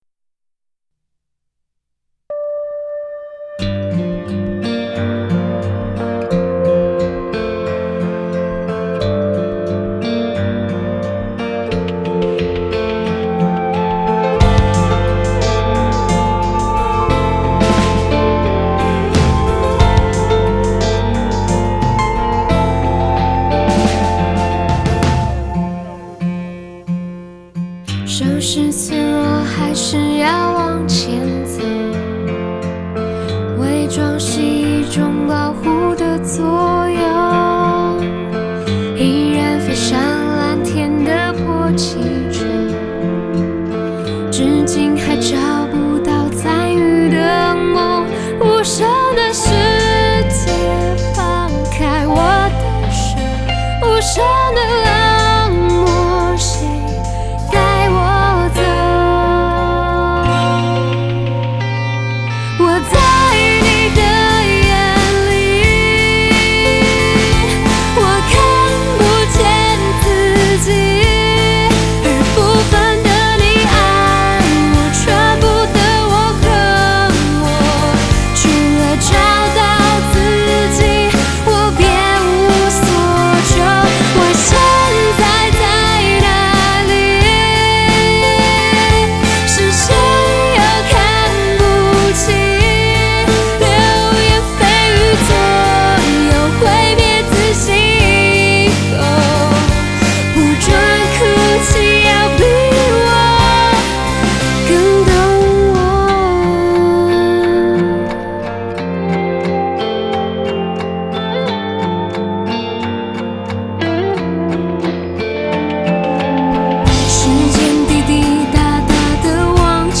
清亮而朴质的嗓音，丰沛自然的创作力
曲风里散发着在森林漫游的小清新舒服质感
孤独却带着温度感的新女声